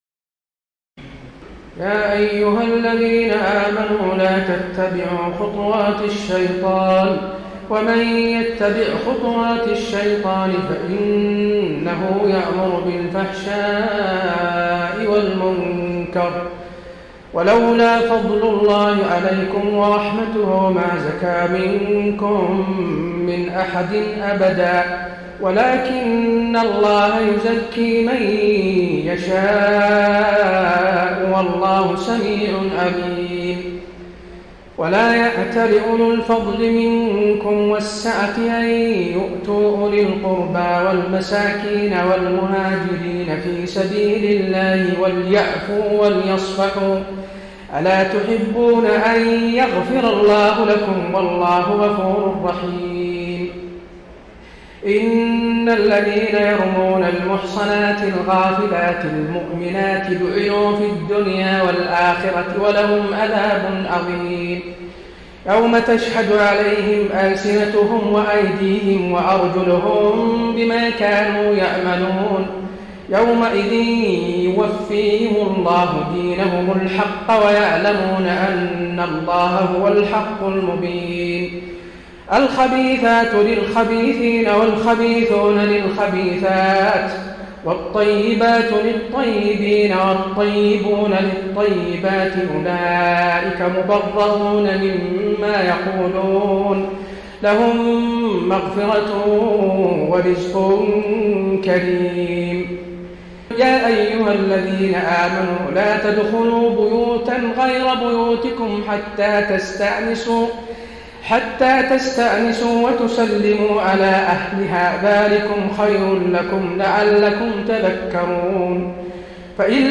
تراويح الليلة السابعة عشر رمضان 1433هـ من سورتي النور (21-64) و الفرقان (1-20) Taraweeh 17 st night Ramadan 1433H from Surah An-Noor and Al-Furqaan > تراويح الحرم النبوي عام 1433 🕌 > التراويح - تلاوات الحرمين